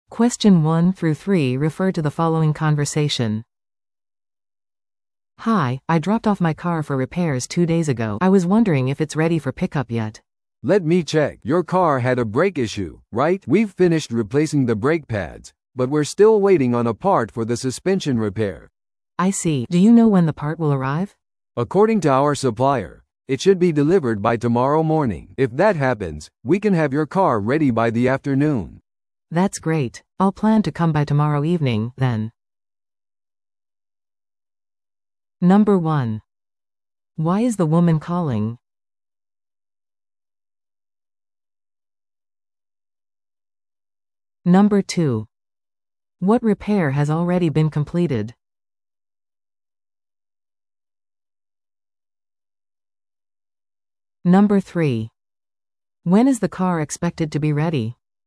PART3は二人以上の英語会話が流れ、それを聞き取り問題用紙に書かれている設問に回答する形式のリスニング問題です。